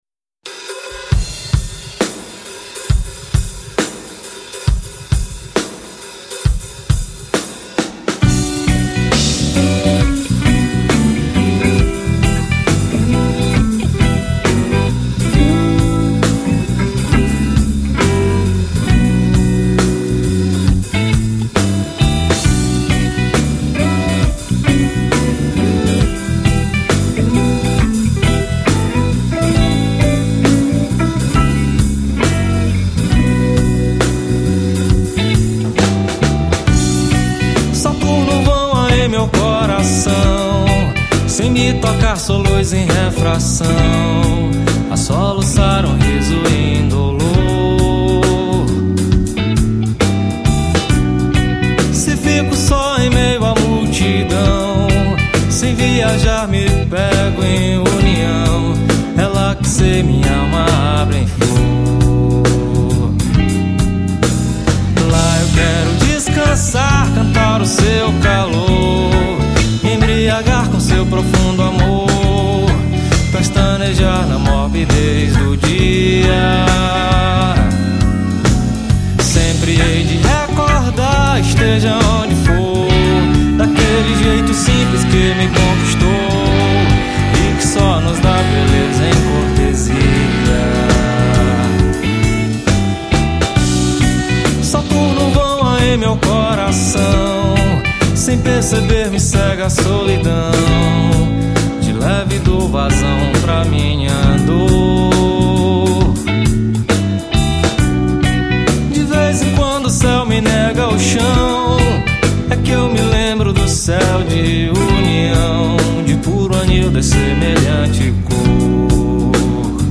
1922   04:10:00   Faixa:     Rock Nacional